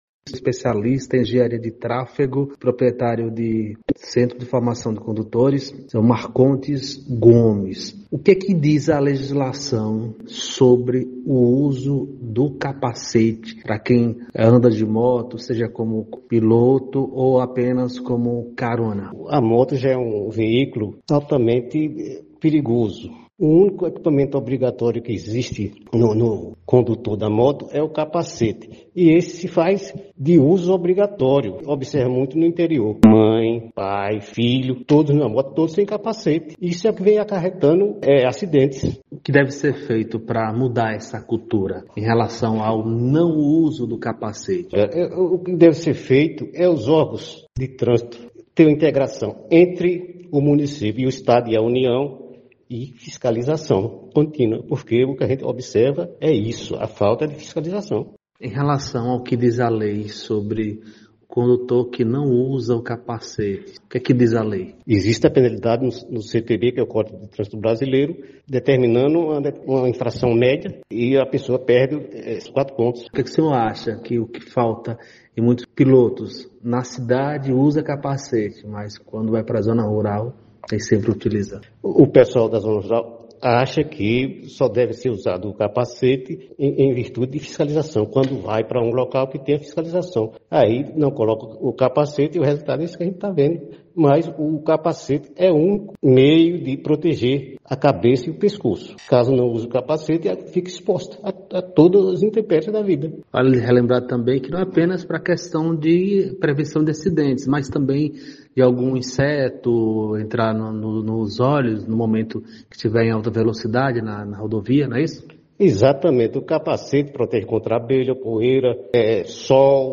Especialista de trânsito, e comerciante falam sobre o uso do capacete de motos.